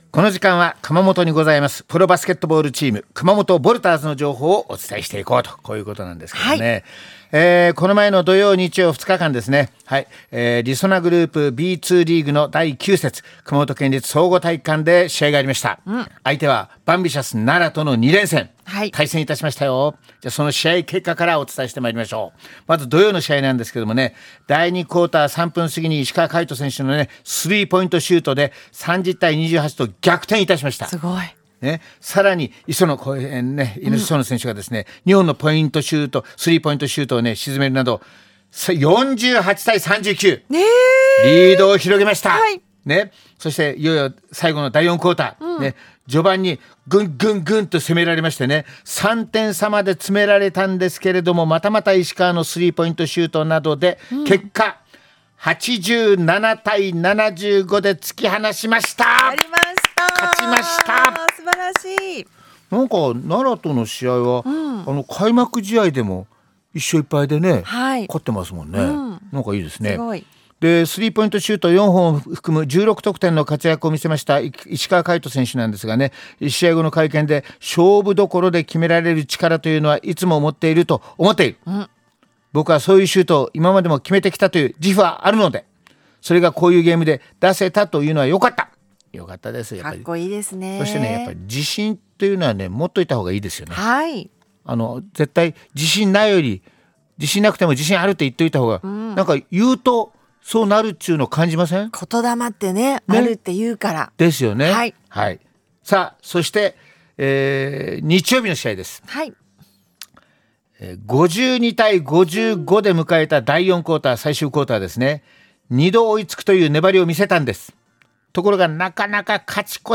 今回は奈良戦振り返りと石川海斗選手のインタビューをお送りします